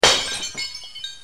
breakingglass2.wav